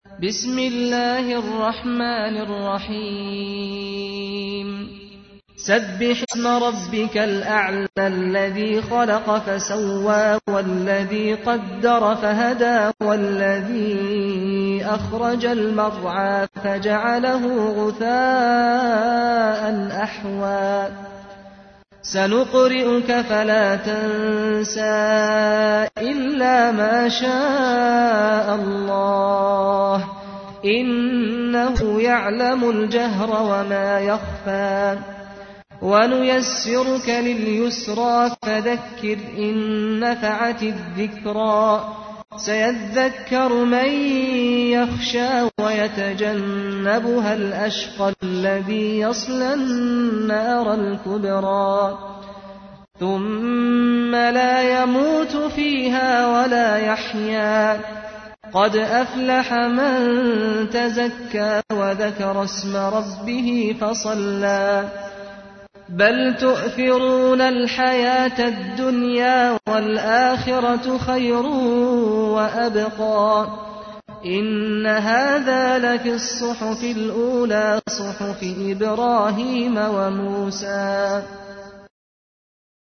تحميل : 87. سورة الأعلى / القارئ سعد الغامدي / القرآن الكريم / موقع يا حسين